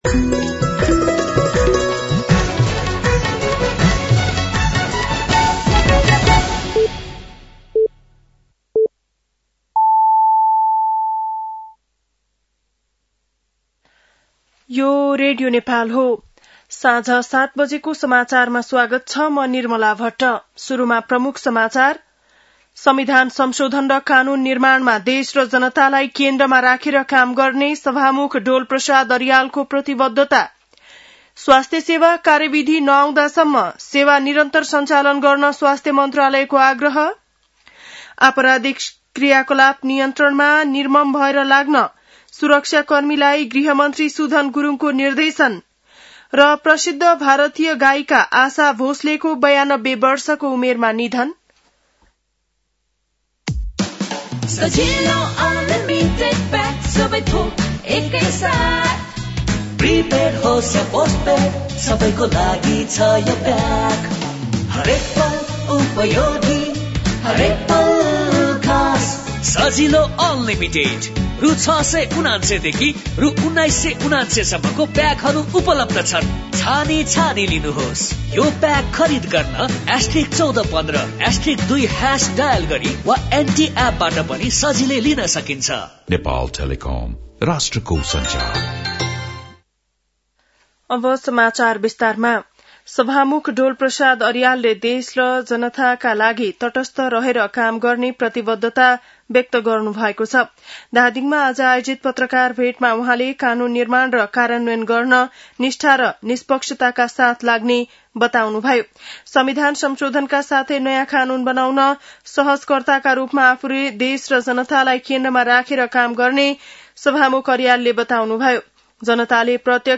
बेलुकी ७ बजेको नेपाली समाचार : २९ चैत , २०८२
7.-pm-nepali-news-1-3.mp3